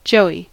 joey: Wikimedia Commons US English Pronunciations
En-us-joey.WAV